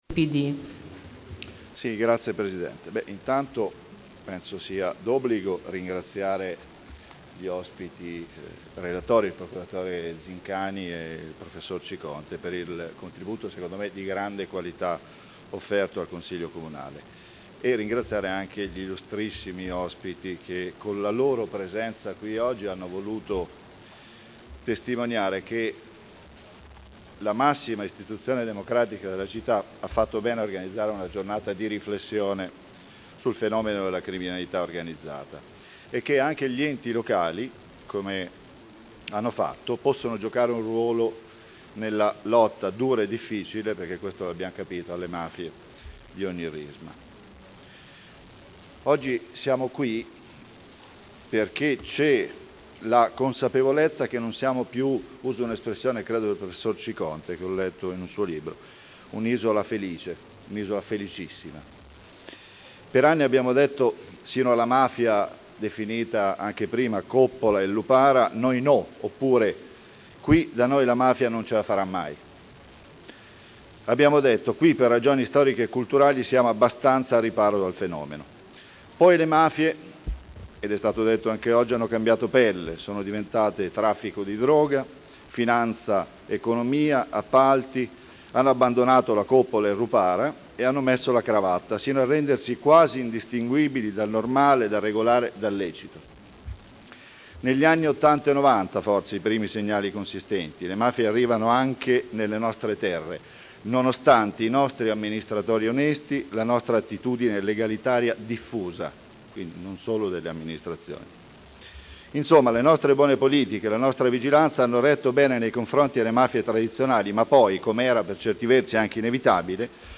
Intervento del Consigliere Paolo Trande al Consiglio Comunale su: Politiche di prevenzione e contrasto alle infiltrazioni mafiose.